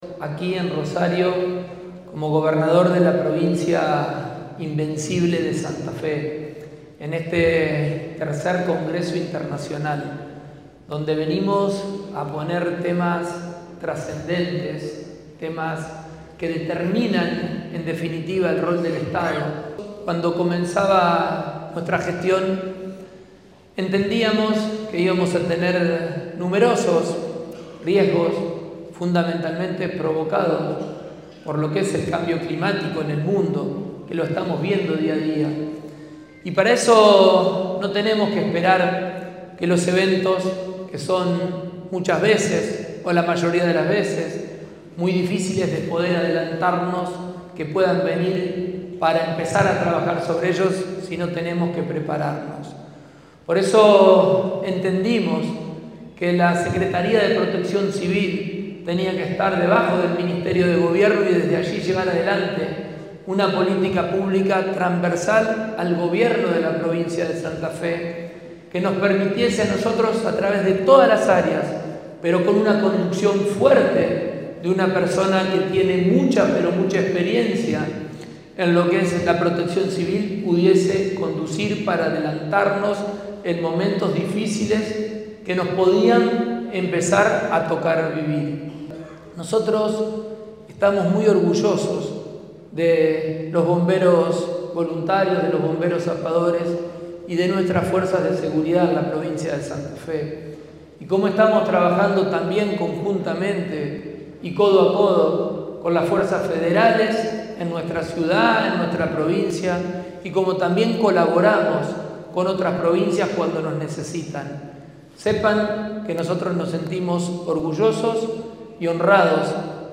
El gobernador lo señaló en la apertura del Congreso Internacional de Protección Civil y Emergencias Complejas que se desarrolla en Rosario.
Fragmentos del discurso del gobernador